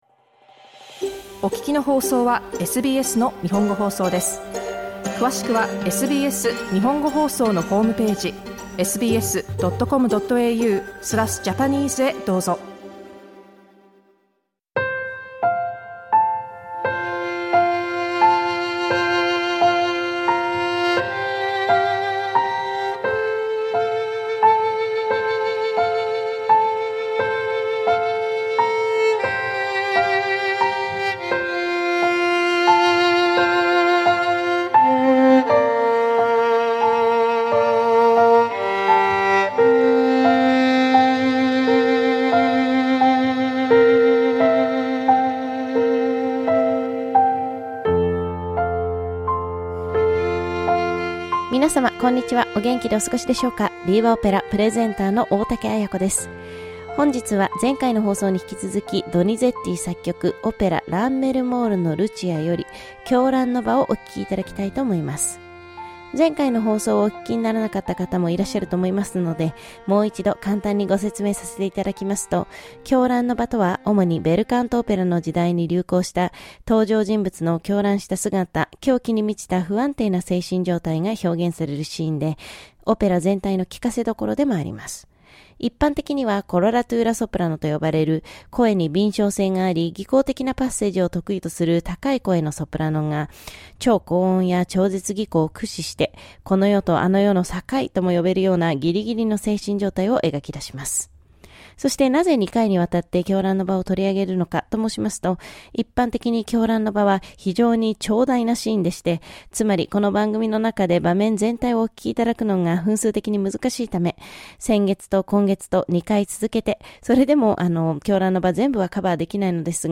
Listen to SBS Japanese Audio on Tue, Thu and Fri from 1pm on SBS 3.